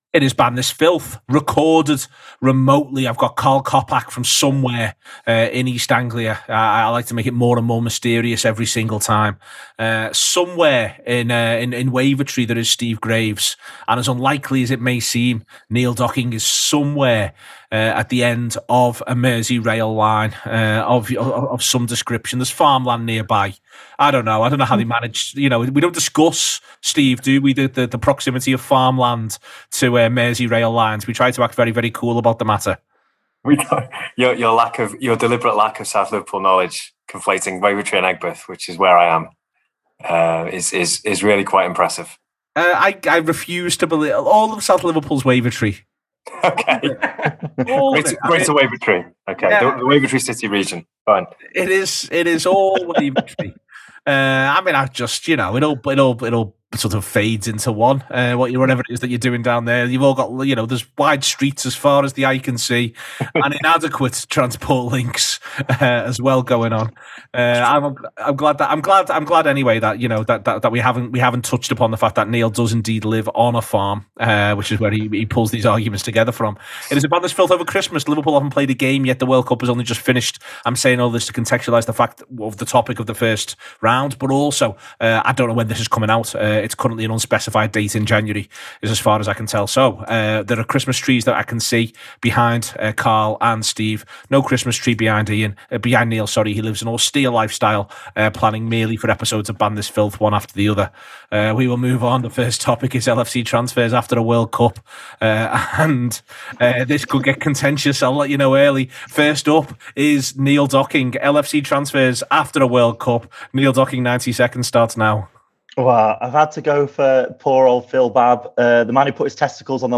as the panel debate what they’d ban from the following categories: